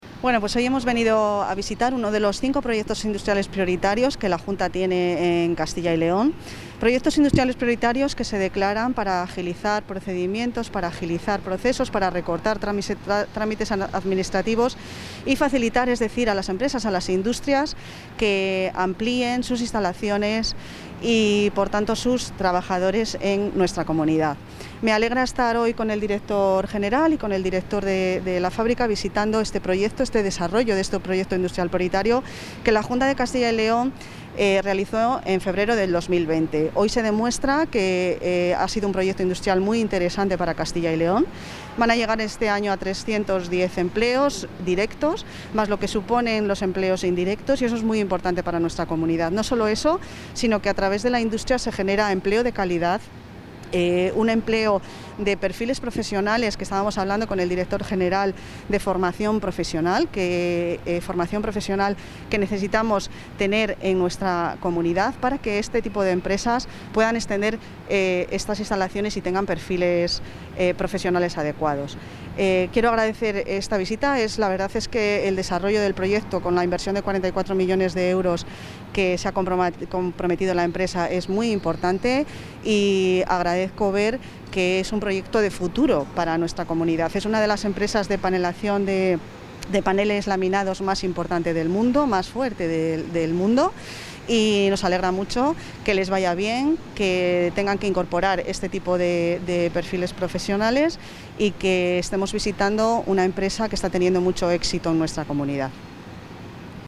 Valoraciones de la consejera de Empleo e Industria.